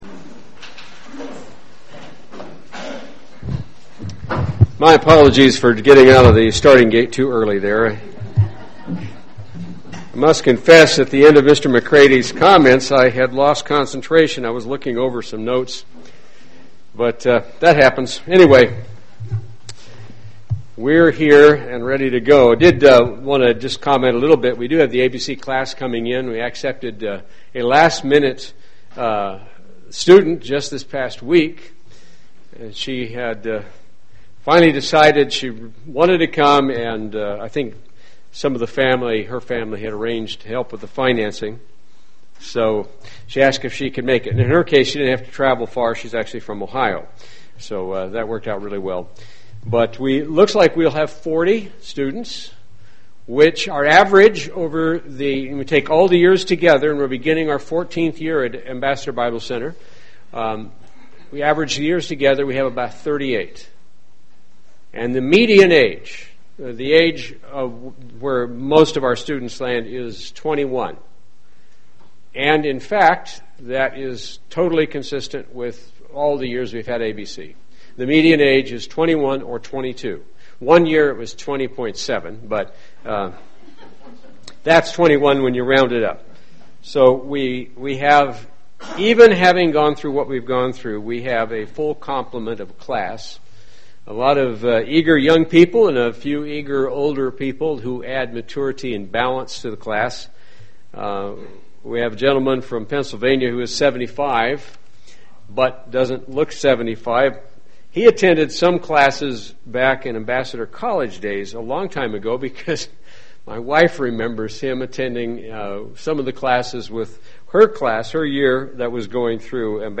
Print 1 Peter 4:12 Beloved don't think it strange conerning the fiery trial which is to try you, UCG Sermon Studying the bible?